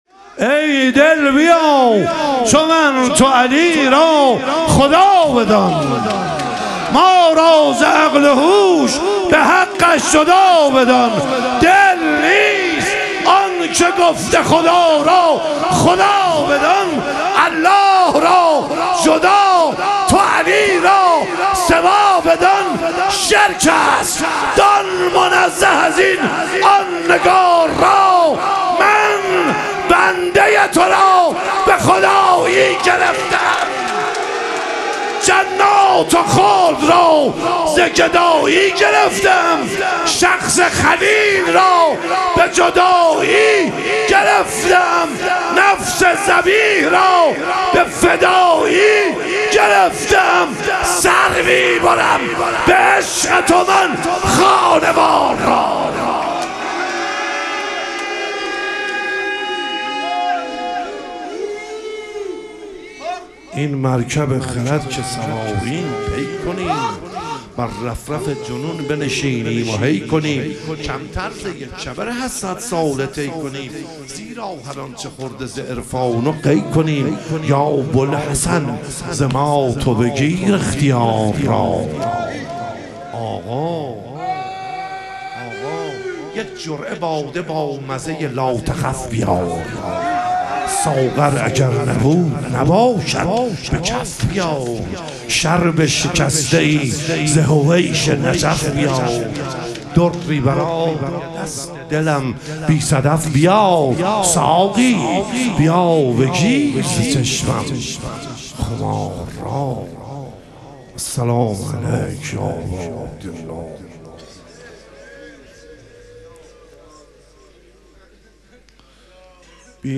شهادت امام جواد علیه السلام - مدح و رجز